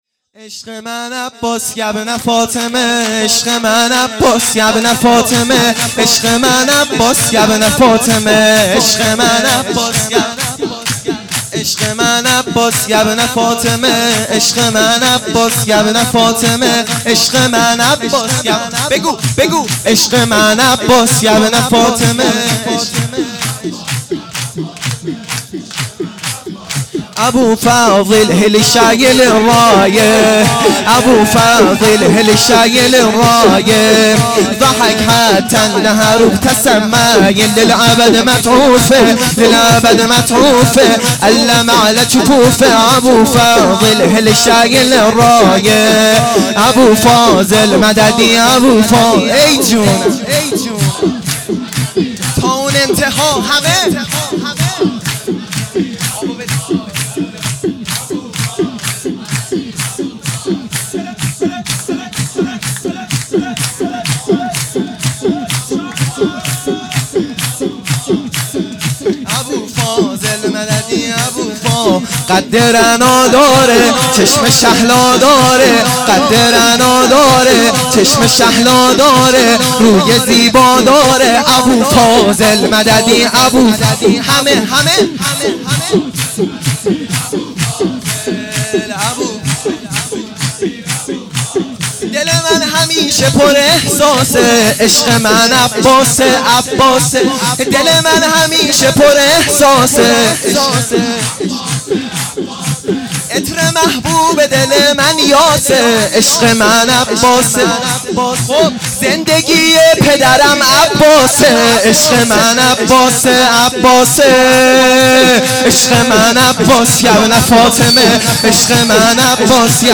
سرود
میلاد امام رضا علیه السلام